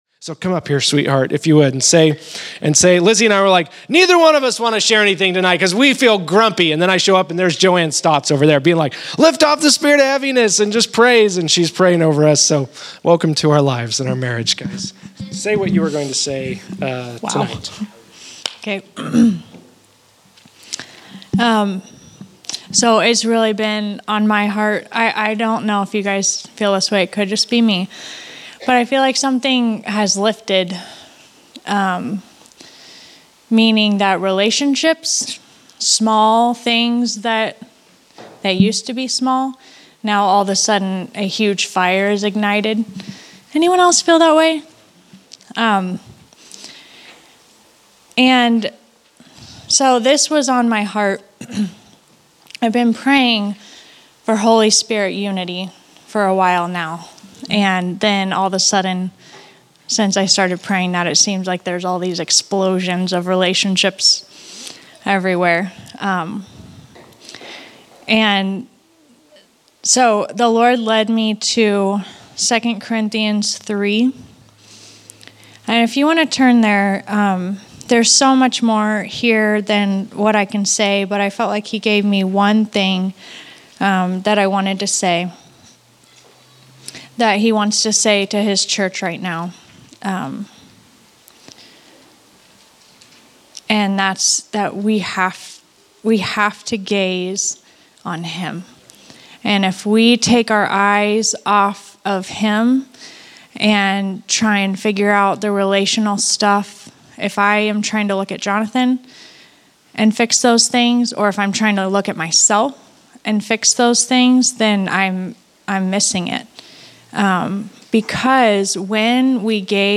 Category: Encouragements      |      Location: El Dorado